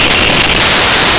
Goofy Sound Effects
Dishes